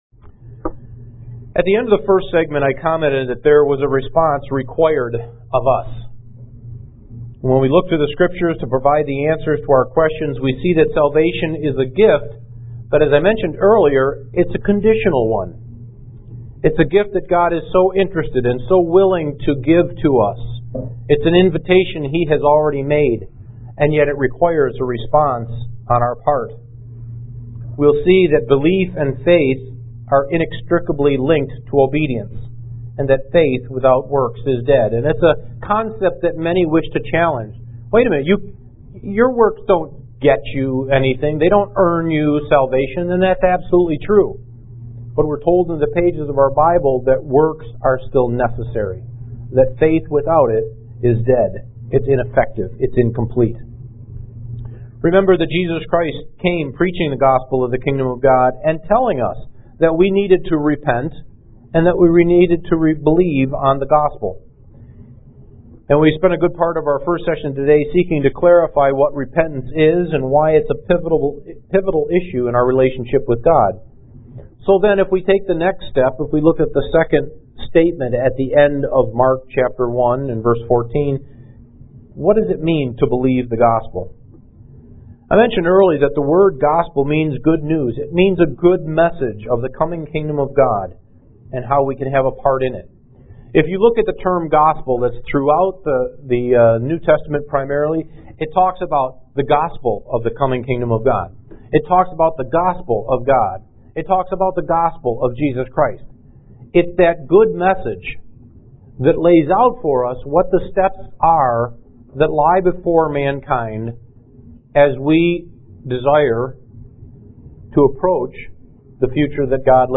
Kingdom of God Seminar 4: Part 2 UCG Sermon Studying the bible?